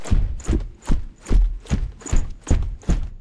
Sounds / Character / Boss / run_a.wav
run_a.wav